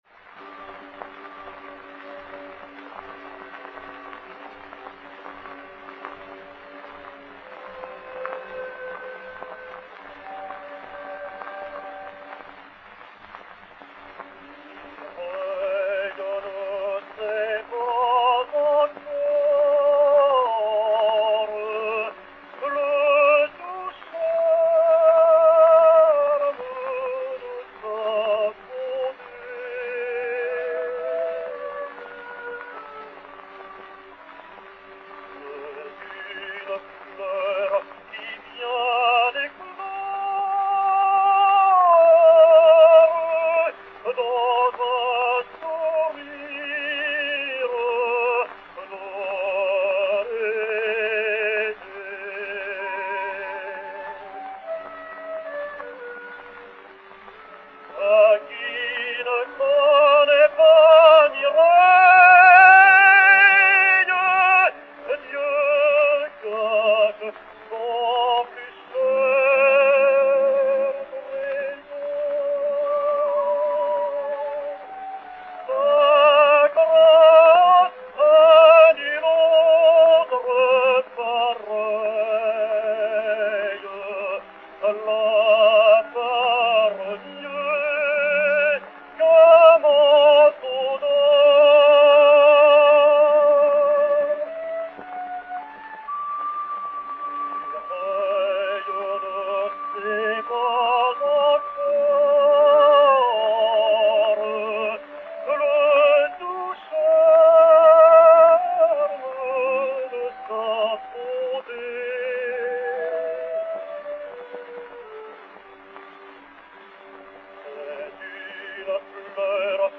Albert Vaguet, ténor, et orchestre